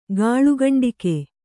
♪ gāḷu gaṇḍike